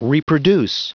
Prononciation du mot reproduce en anglais (fichier audio)
Prononciation du mot : reproduce